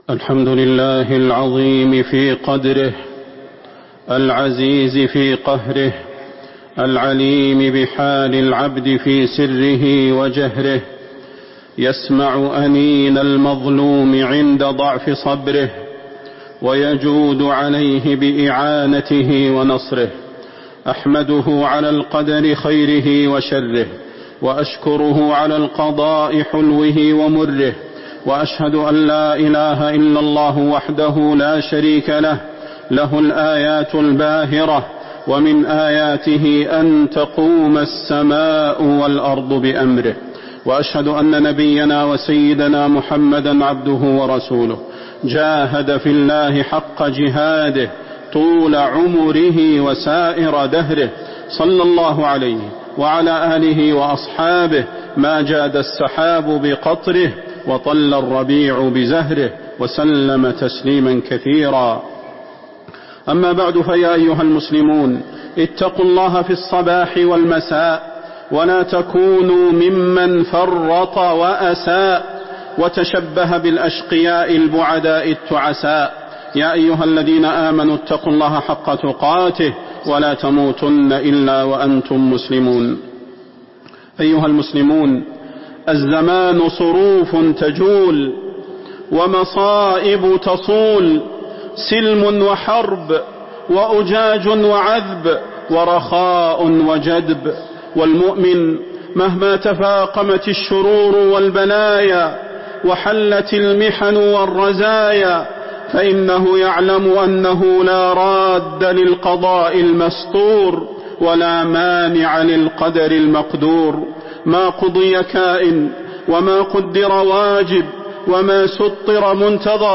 تاريخ النشر ٣ جمادى الأولى ١٤٤٥ هـ المكان: المسجد النبوي الشيخ: فضيلة الشيخ د. صلاح بن محمد البدير فضيلة الشيخ د. صلاح بن محمد البدير عاقبة الظلم